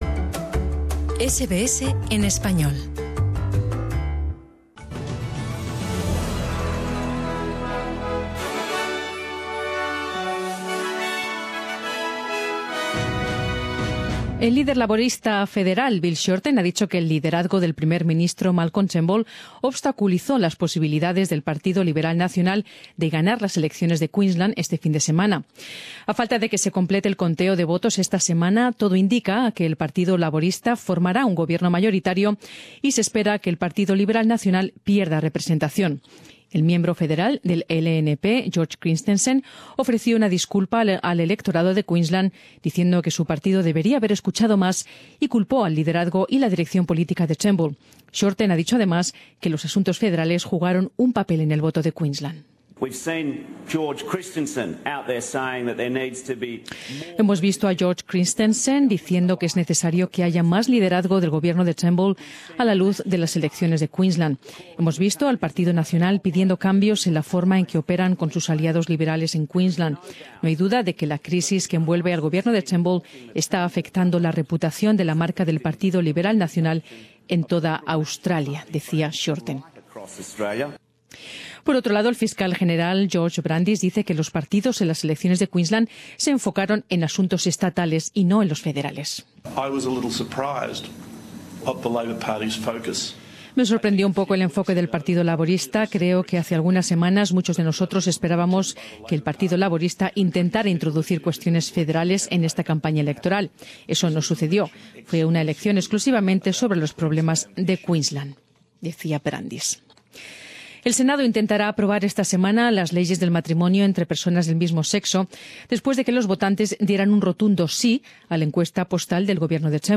Extracto del boletín de noticias de SBS